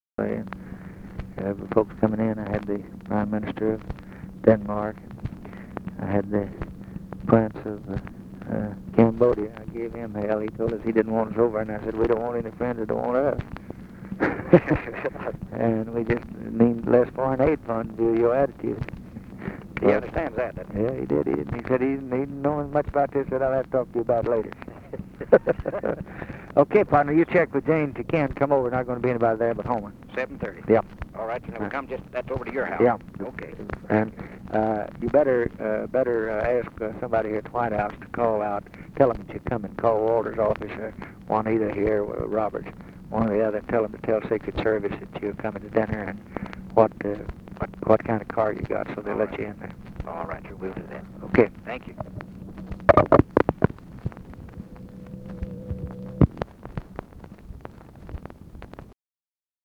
Conversation with JOE KILGORE, November 27, 1963
Secret White House Tapes